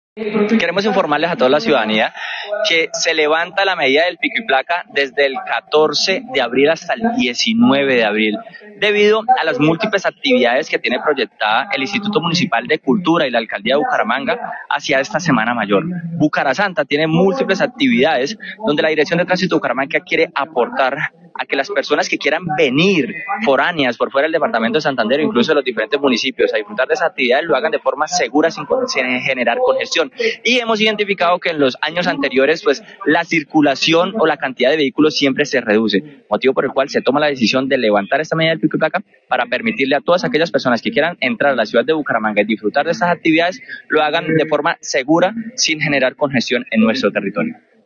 Jhair Manrique, Director de Tránsito de Bucaramanga